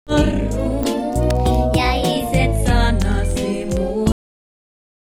Note that the input signal already contains impulsive "cracks", which some of the effects may emphasize.
Chorus/flanger
Vibrato: delay 60 ms, modulation frequency 0.3 Hz, modulation depth 60 ms, feedback 0 , feedforward 1 , blend 0